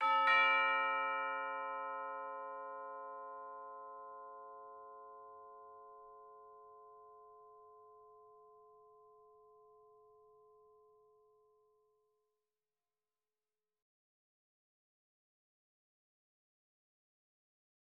Doorbell Chime Sound Effect
bell chime chimes chiming ding doorbell note orchestral sound effect free sound royalty free Sound Effects